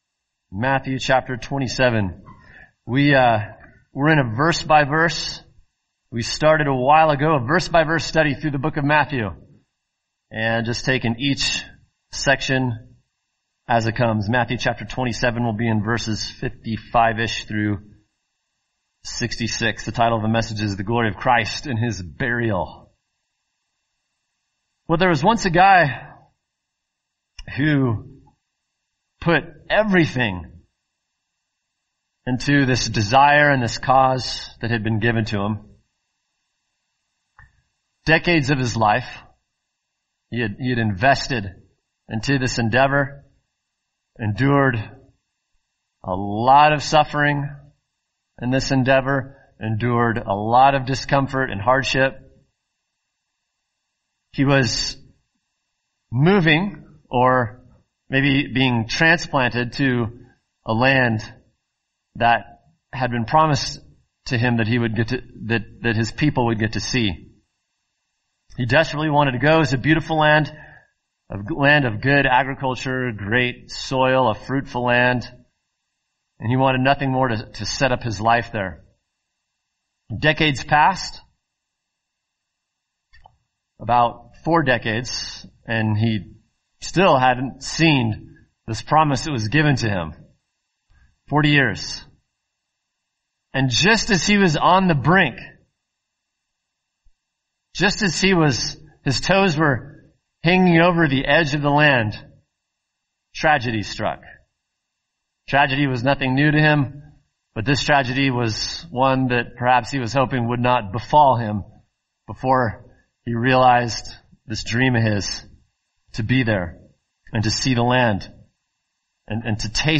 [sermon] Matthew 27:57-66 The Glory of Christ in His Burial | Cornerstone Church - Jackson Hole